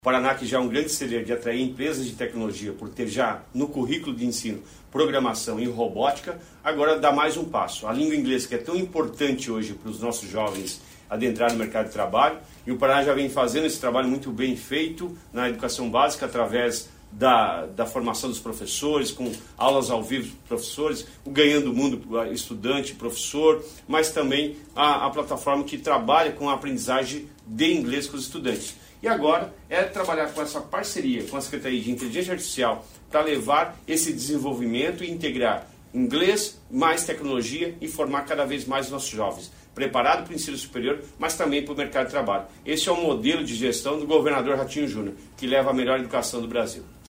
Sonora do secretário Estadual da Educação, Roni Miranda, sobre o Paraná Global